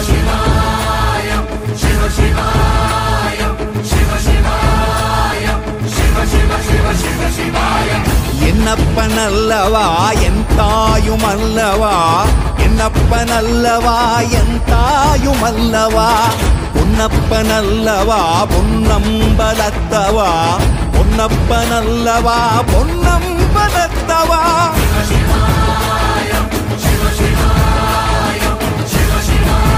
best flute ringtone download | love song ringtone
romantic ringtone download